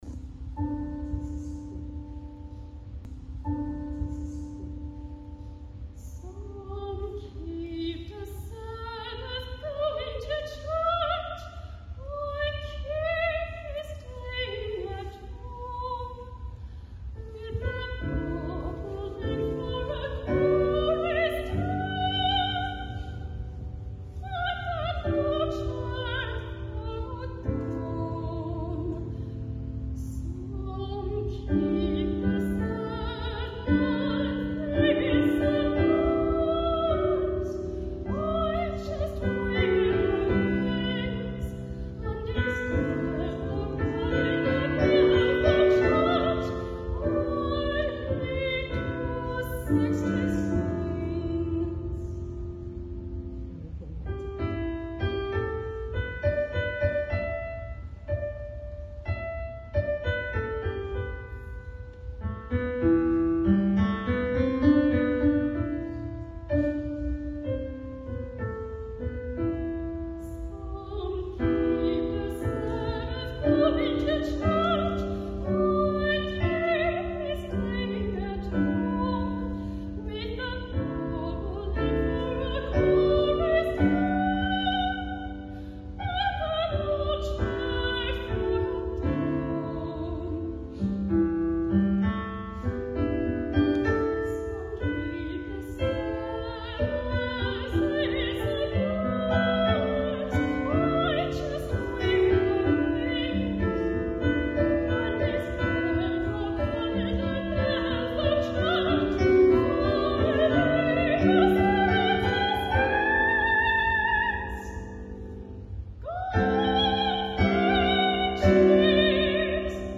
for high or medium voice and piano
soprano
piano
Fudan Art Museum, Shanghai, China, 2025